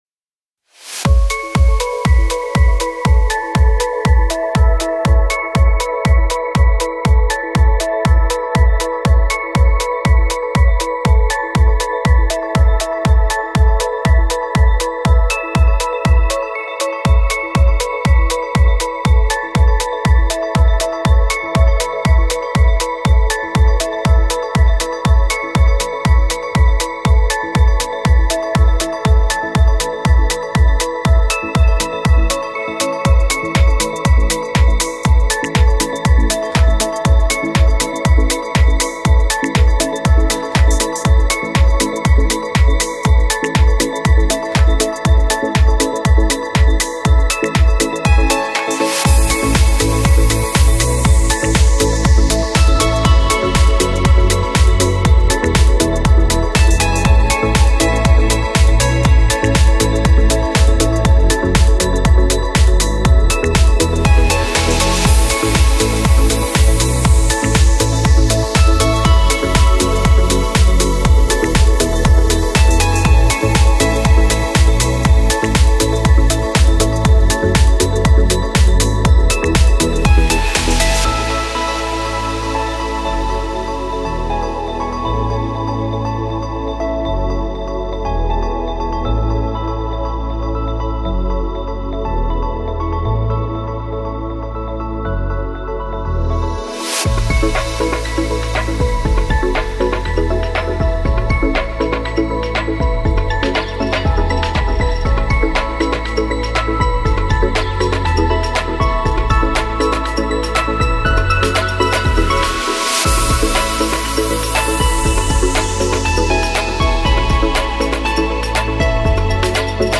Стиль: Melodic Progressive